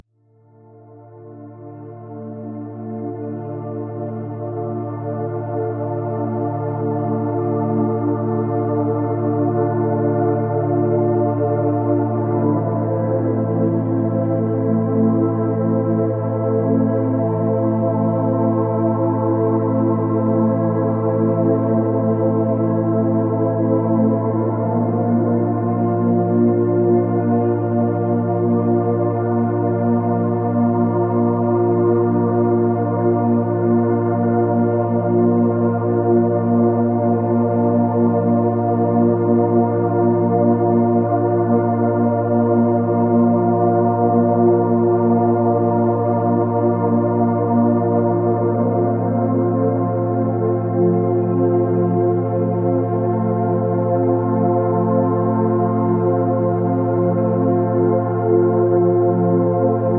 Tap into divine healing with 111 Hz – the frequency of cell rejuvenation and spiritual awakening.